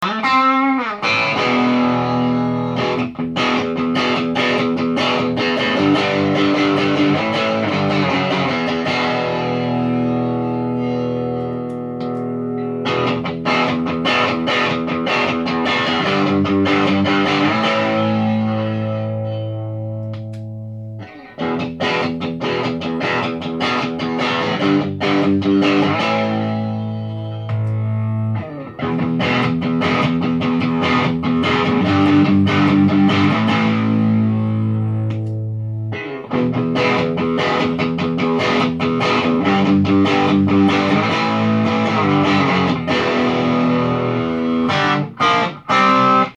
手持ちのやつらを動員しただばよぅ、2時間で実験したのは歪み系だば。
録音したMDから雑音のひどいものを取り除き、アップだば。
思うだば。マクソンがさすがにチューブ・サウンド、SD-2のリードモードは太めに